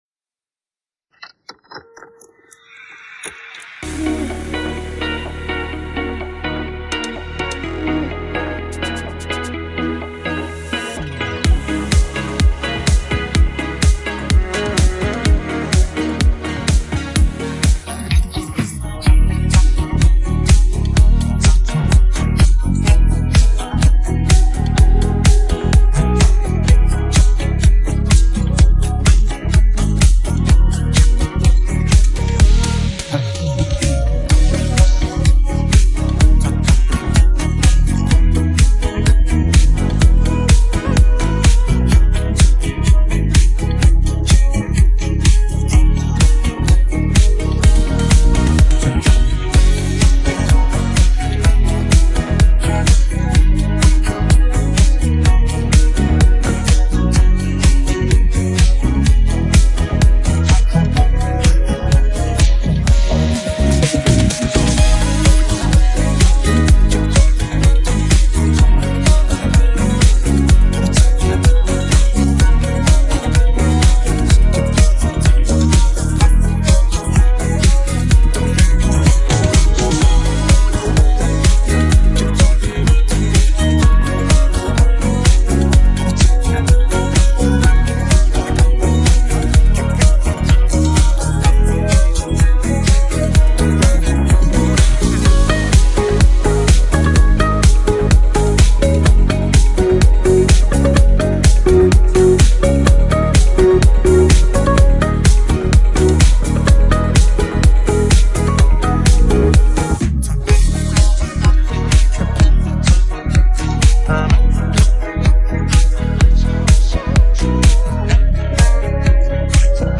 پخش نسخه بی‌کلام
download-cloud دانلود نسخه بی کلام (KARAOKE)